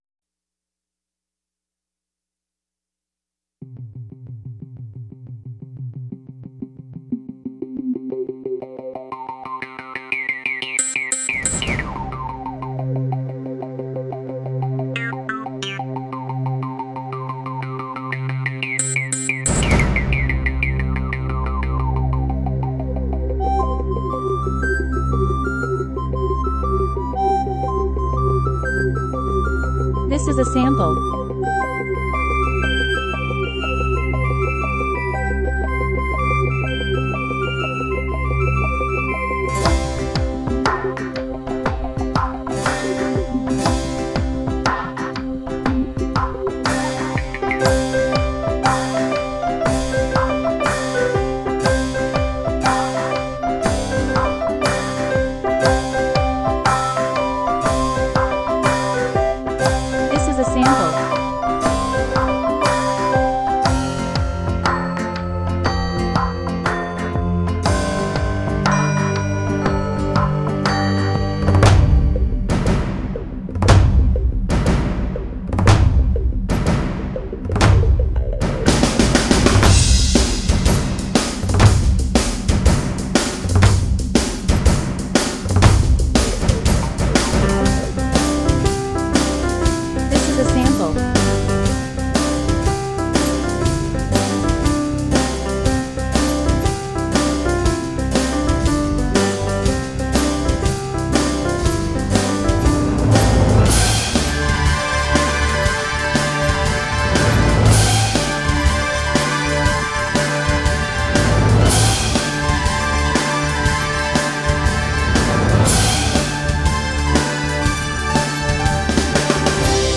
Our hearts warming from the scene we just witnessed, a little cottage catches our attention with the lively sound of a Scottish flavored tale of I SAW THREE SHIPS.